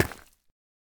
Minecraft Version Minecraft Version 1.21.5 Latest Release | Latest Snapshot 1.21.5 / assets / minecraft / sounds / block / deepslate / break1.ogg Compare With Compare With Latest Release | Latest Snapshot
break1.ogg